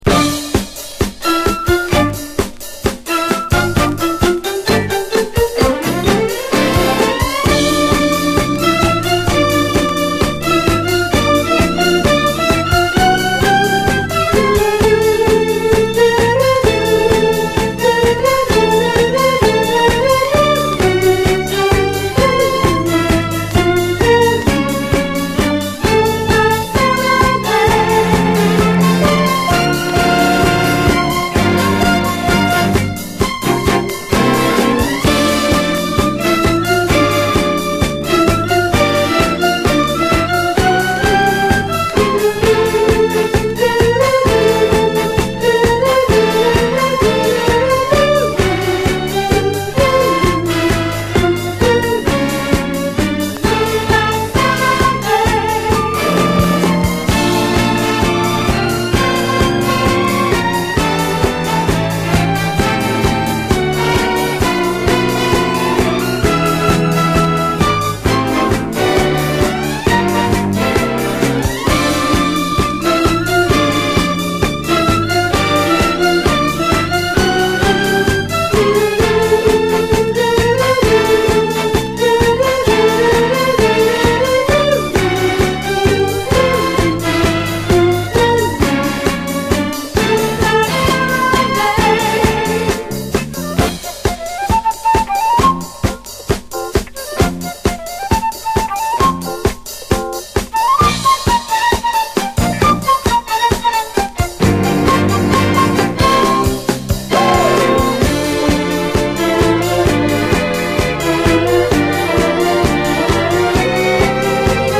SOUL, 70's～ SOUL, DISCO, 7INCH
UK産70’Sラウンジー・ディスコ45！